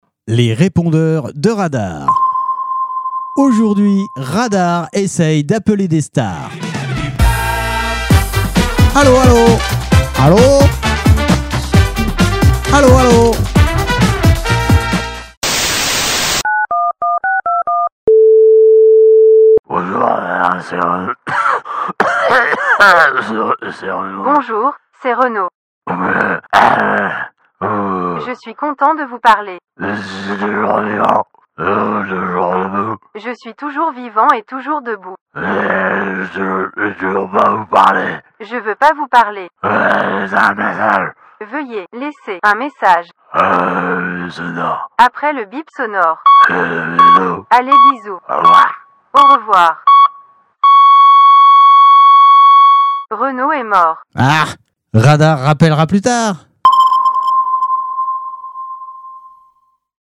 Les répondeurs de Radar parodies répondeurs stars radar